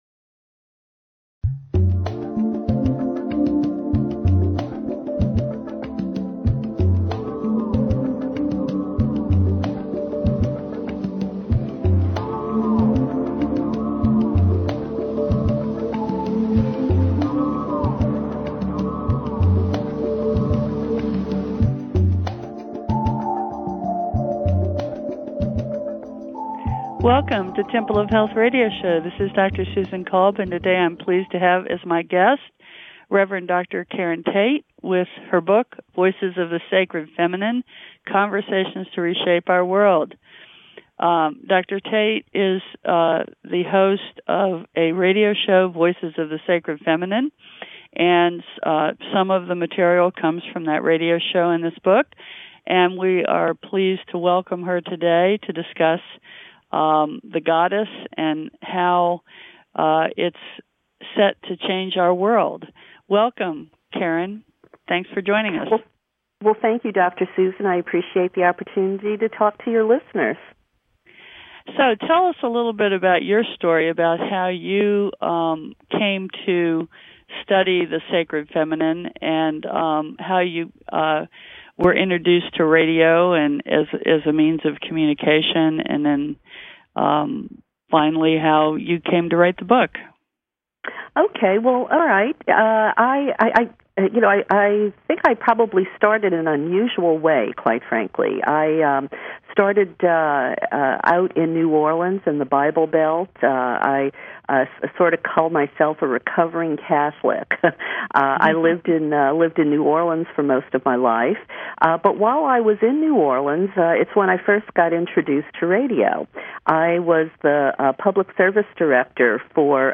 Talk Show Episode
interviewing special guest